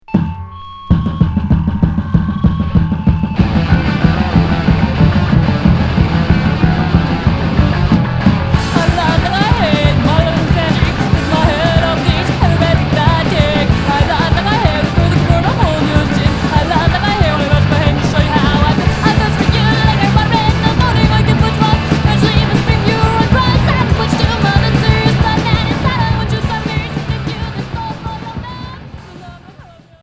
Punk rock
enregistré en Californie